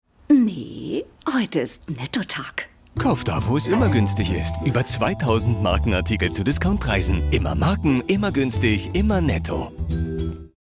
sehr variabel, markant
Mittel plus (35-65)
Presentation